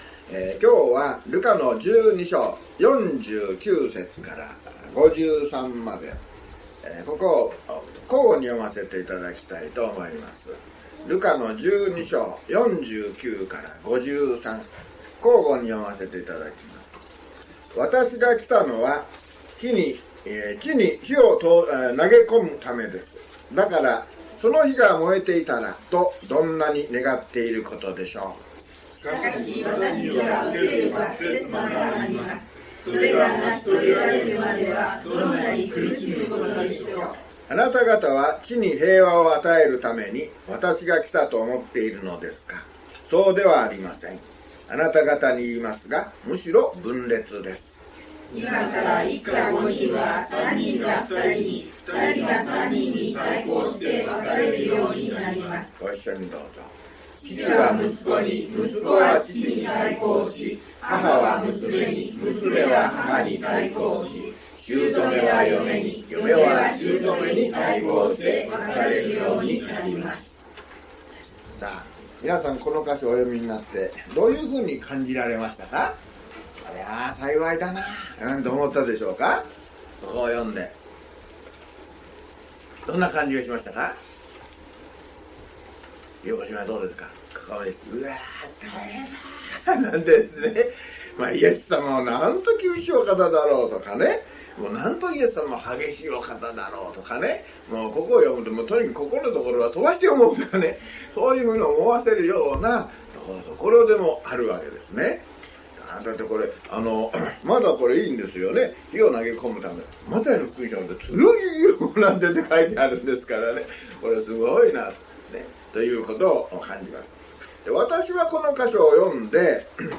luke095mono.mp3